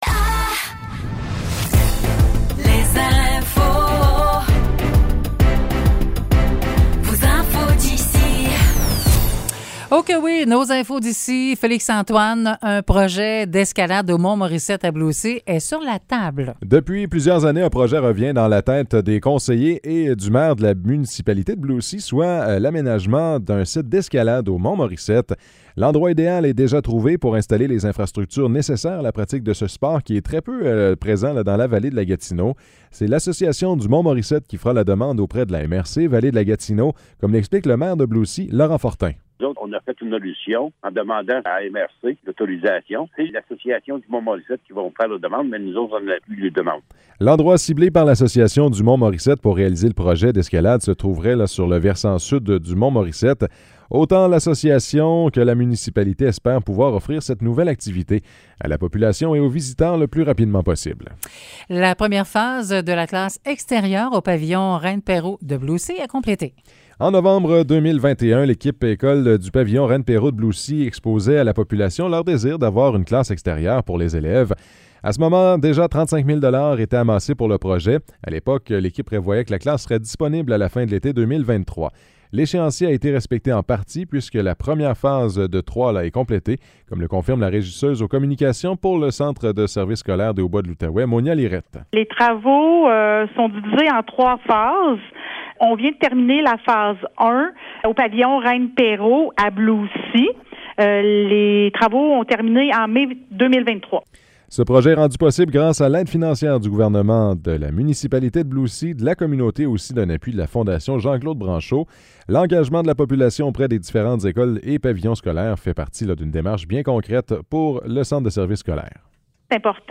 Nouvelles locales - 19 septembre 2023 - 10 h